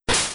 menu_open.wav